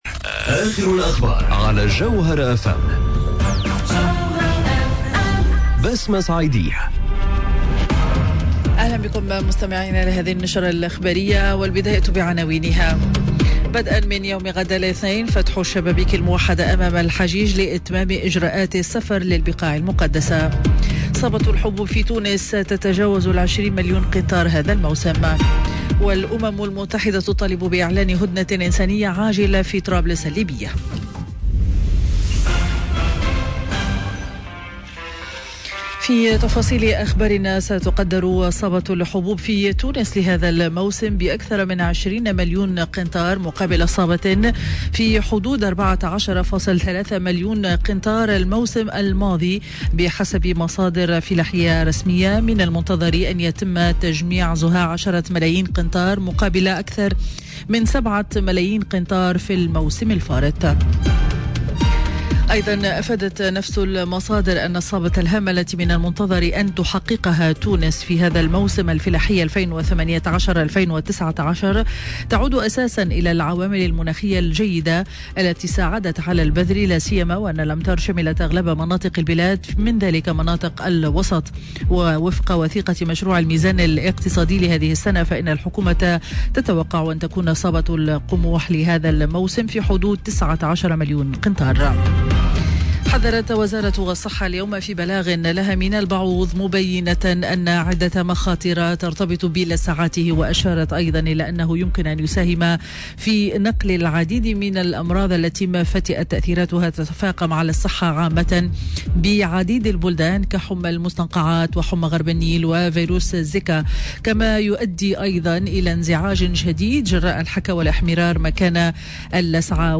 نشرة أخبار منتصف النهار ليوم الأحد 09 جوان 2019